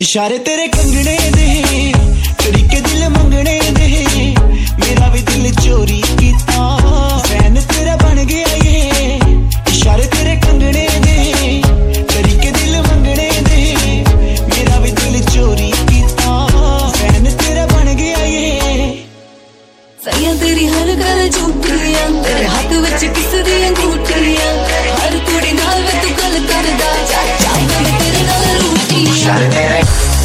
Categories Punjabi Ringtones